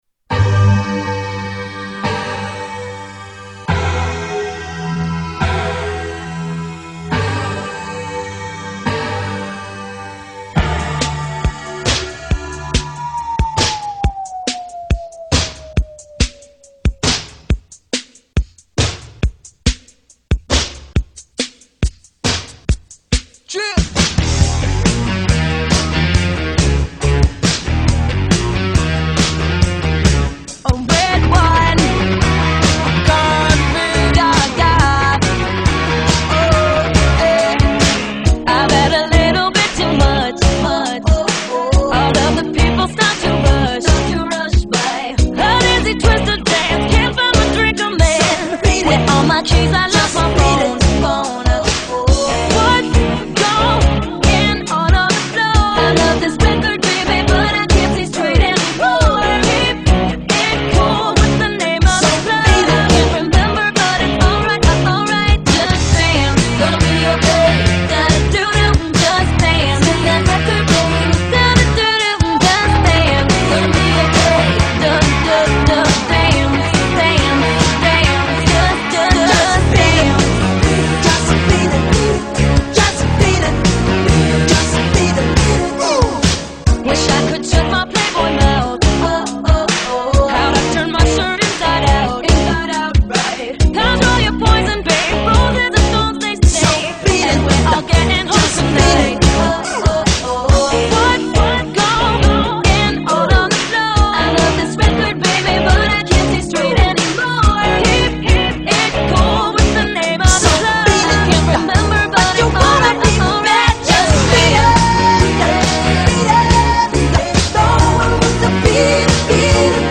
Mash up hit songs